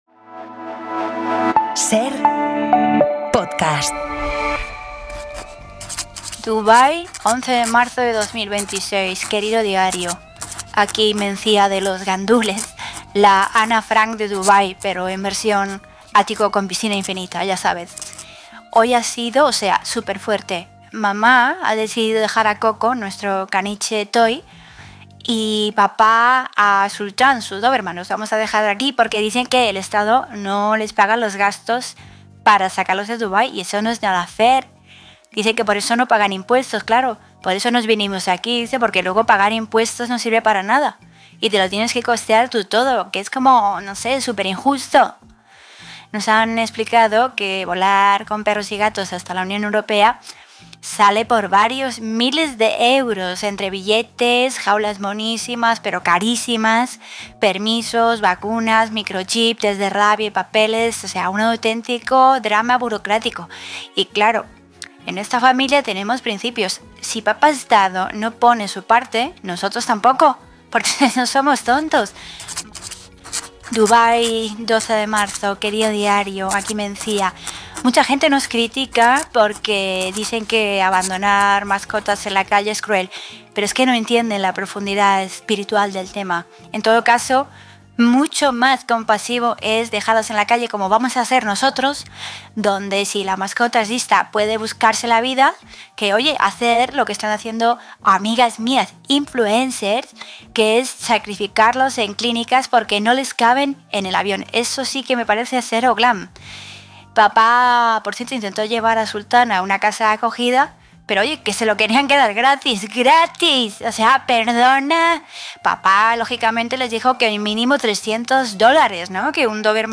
Audio de Humor en la Cadena SER en Podium Podcast